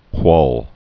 (kwôl)